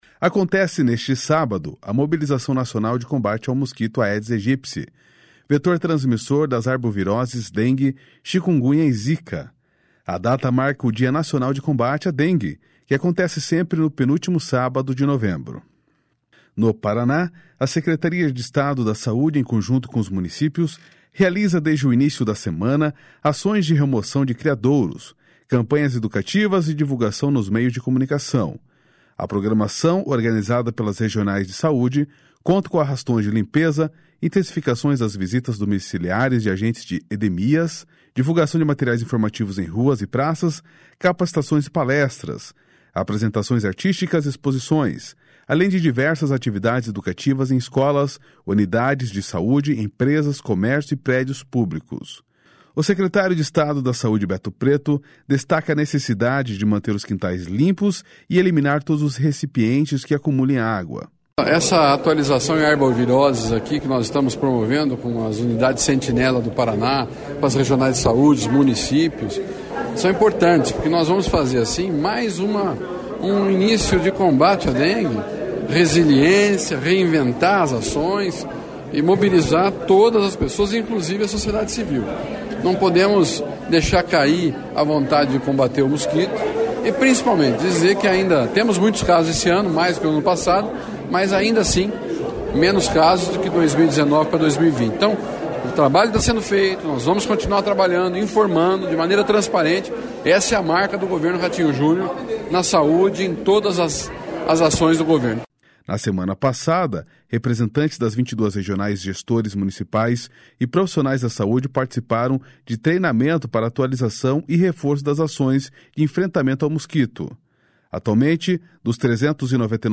O secretário de Estado da Saúde, Beto Preto, destaca a necessidade de ter manter os quintais limpos e eliminar todos os recipientes que acumulem água.//SONORA BETO PRETO//